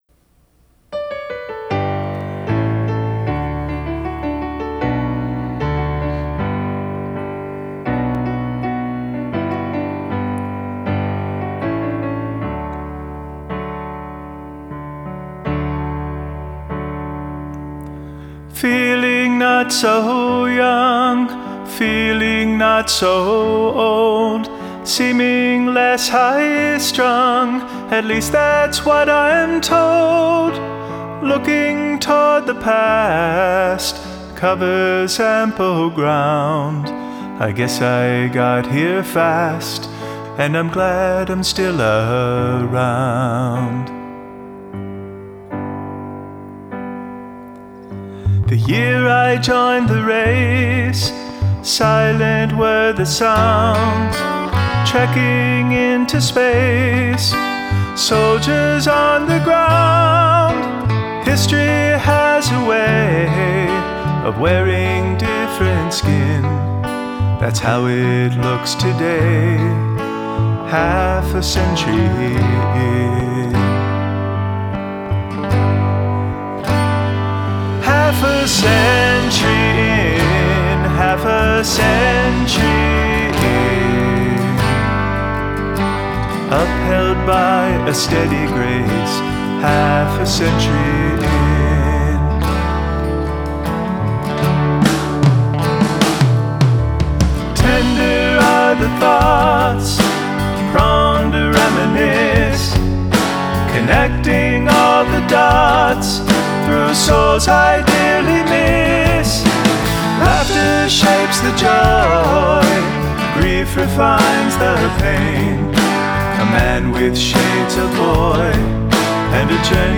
Today, I am sharing an updated version of the song that is a little bit clearer and better produced.